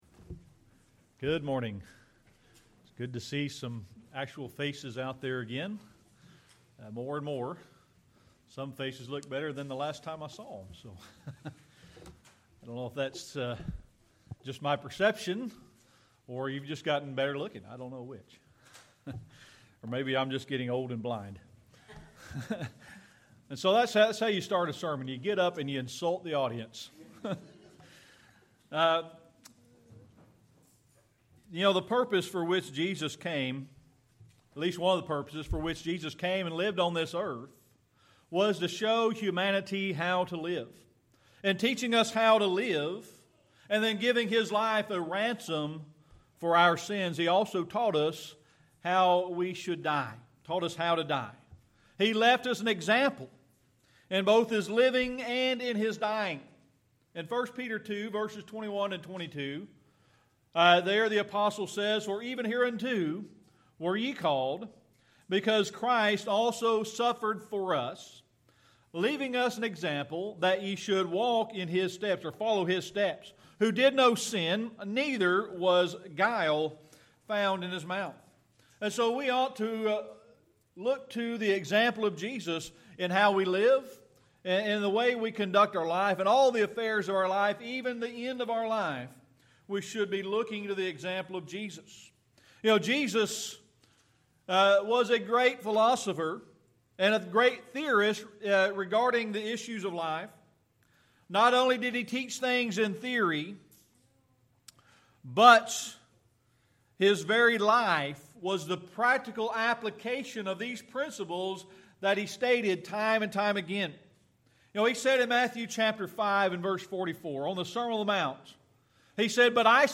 Series: Sermon Archives
Service Type: Sunday Morning Worship